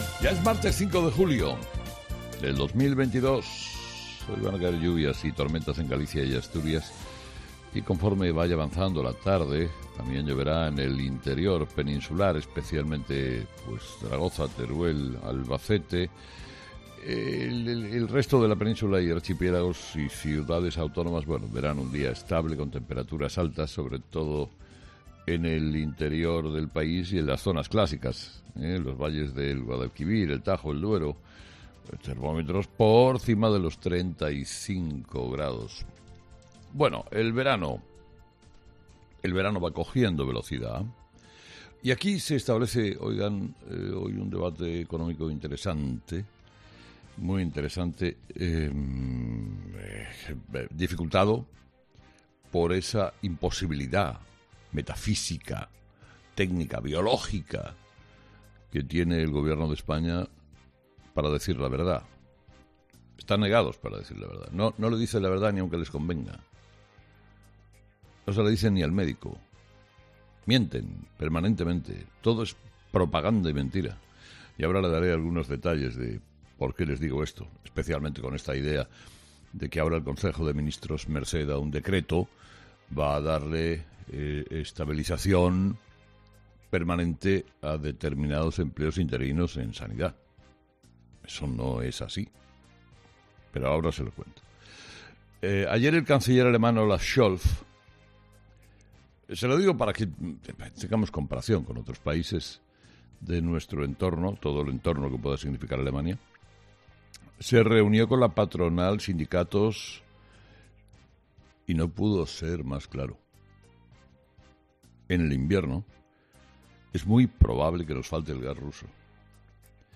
Carlos Herrera, director y presentador de ' Herrera en COPE ', ha comenzado el programa de este martes analizando las principales claves de la jornada, que pasan, entre otros asuntos, por las medidas que va a aprobar este martes el Consejo de Ministros.